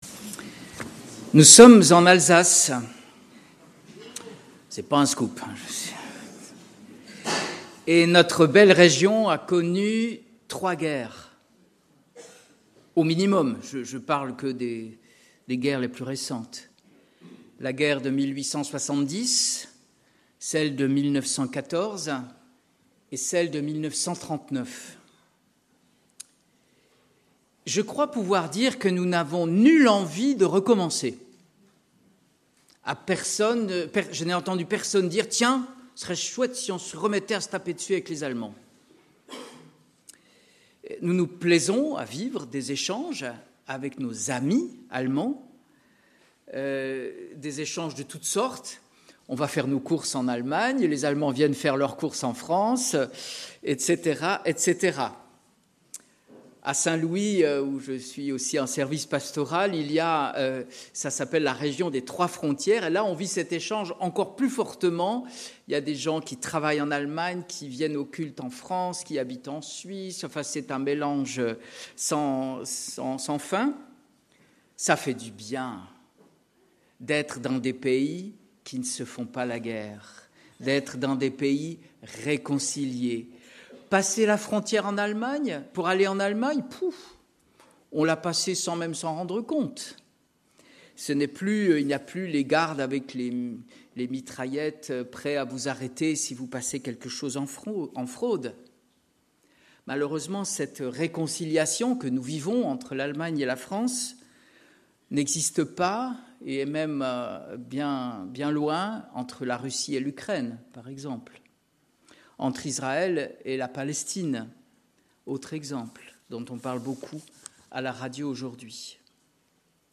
Culte du dimanche 13 avril 2025 – Église de La Bonne Nouvelle
Prédication